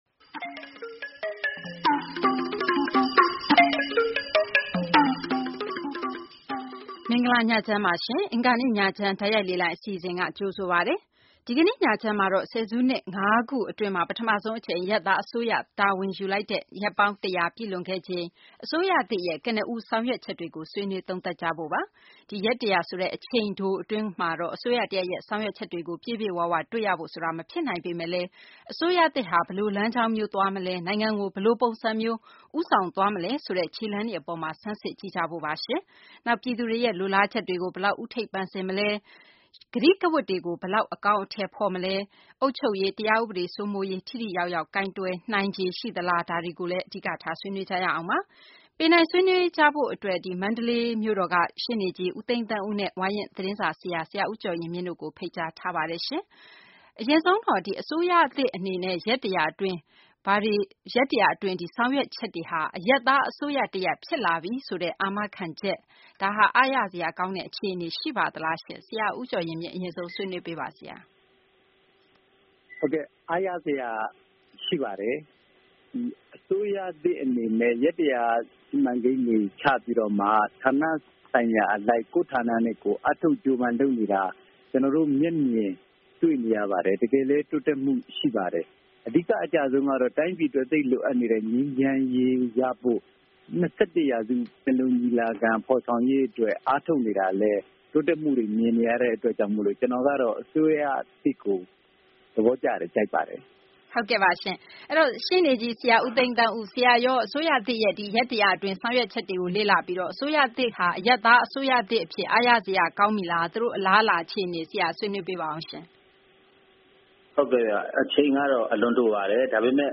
ပြောင်းလဲချိန် တန်ပြီဆိုတဲ့ ကြွေးကြော်သံနဲ့ နှစ်ပေါင်းများစွာ အတွင်း ပထမဆုံး အရပ်သား အစိုးရသစ်အဖြစ် တာဝန်ယူခဲ့တဲ့ အမျိုးသား ဒီမိုကရေစီ အဖွဲ့ချုပ် NLD အစိုးရရဲ့ ပထမဆုံး ရက်ပေါင်း ၁၀၀ တာဝန်ယူမှု အတွင်း ပြည်သူတွေရဲ့ လိုလားချက်ကို ဘယ်လောက် ဦးထိပ်ပန်ဆင်ထားလဲ၊ ဒေါင်ဒေါင်မြည် အရပ်သား အစိုးရသစ် တရပ်အဖြစ် ရပ်တည်လာနိုင်မယ့် အလားအလာတွေကို ဆွေးနွေးထားတဲ့ အင်္ဂါနေ့ ညချမ်းတိုက်ရိုက်လေလှိုင်း အစီအစဉ်ကို နားဆင်နိုင်ပါတယ်။